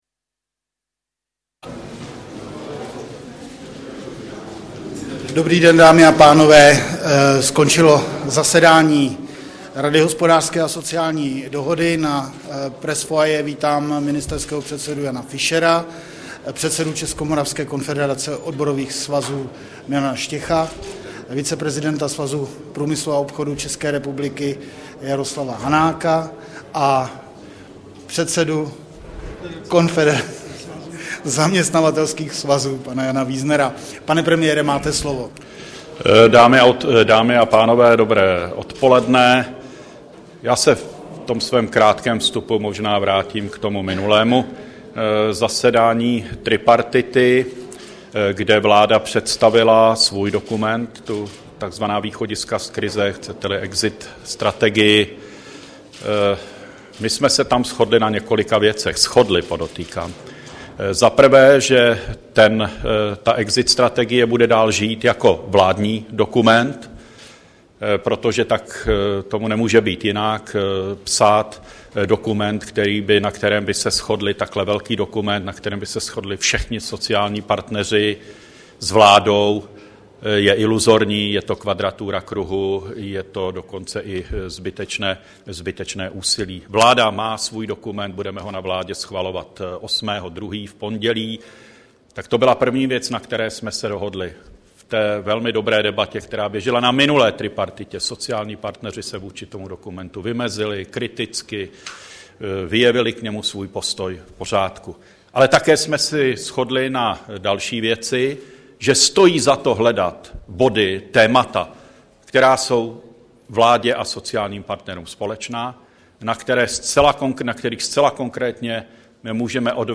Tiskový brífink po mimořádné schůzi tripartity, 2.2.2010